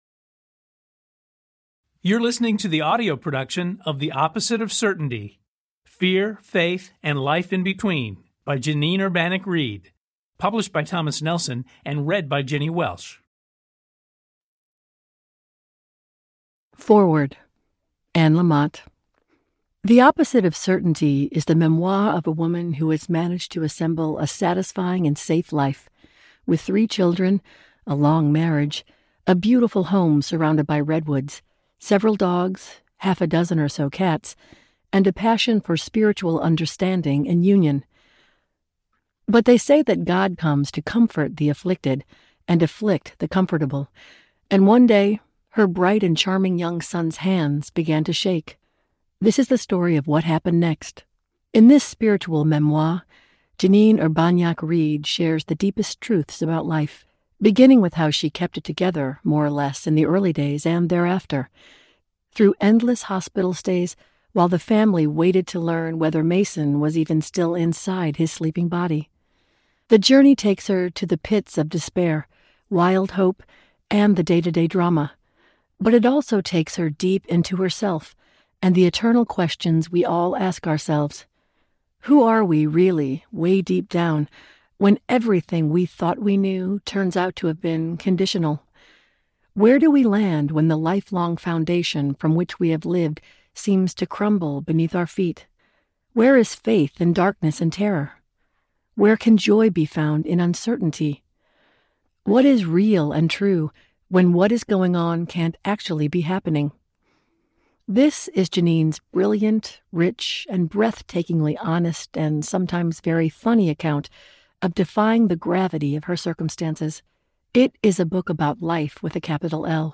The Opposite of Certainty Audiobook
Narrator
7.5 Hrs. – Unabridged